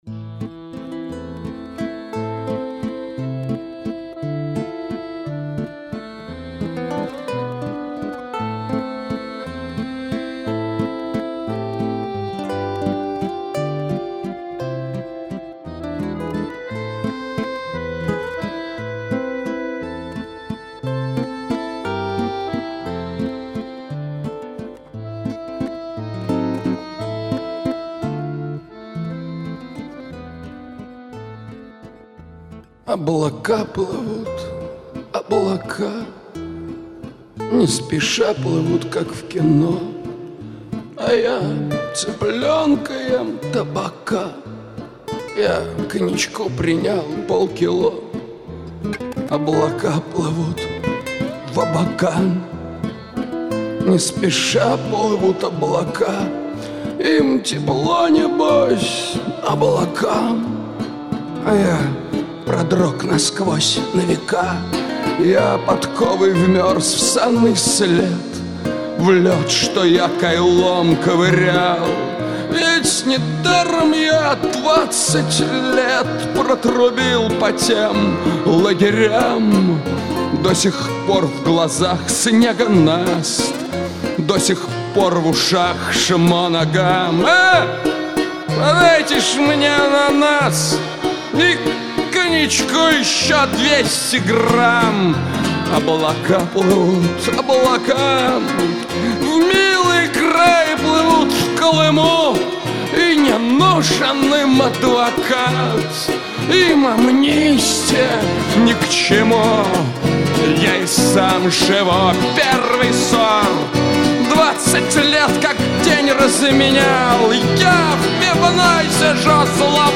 Добавлю двух хороших бардов.....
слишком экспрессивен
для чего там нужен вальс